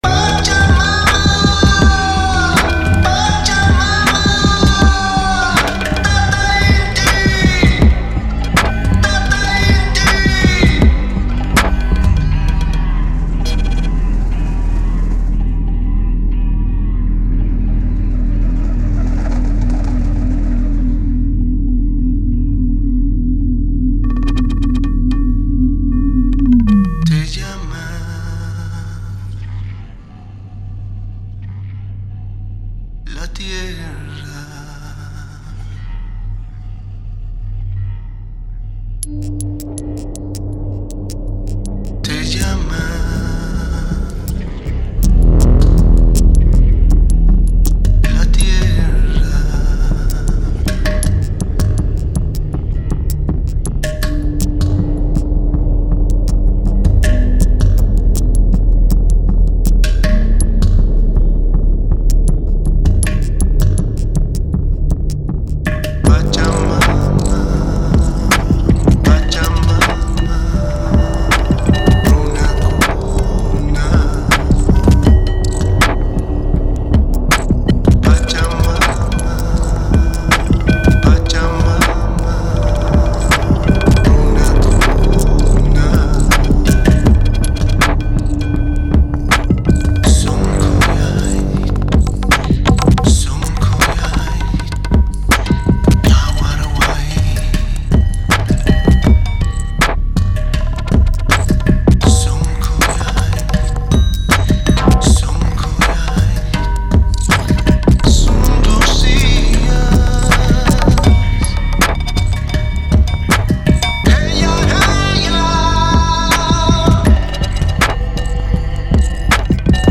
Der peruanische Sänger liefert eine breite Palette an Versen, darunter dominante und daneben auch leise, verhalten vorgetragene. Aus Letzteren füge ich einen Mittelteil zusammen, der sich so anhört: